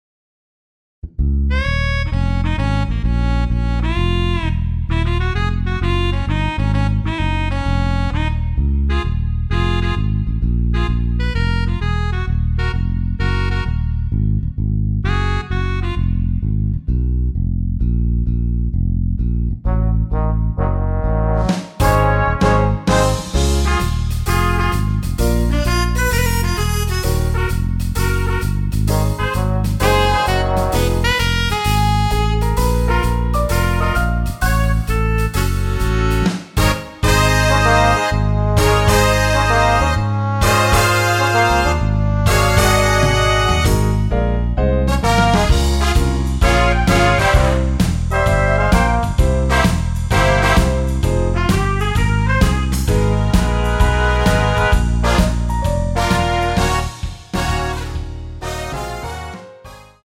대부분의 여성분이 부르실수 있는키로 제작 하였습니다.(미리듣기 참조)
Db
앞부분30초, 뒷부분30초씩 편집해서 올려 드리고 있습니다.
중간에 음이 끈어지고 다시 나오는 이유는